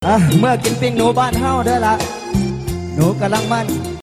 haha_8hqfM9j.mp3